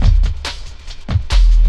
59 LOOP 01-L.wav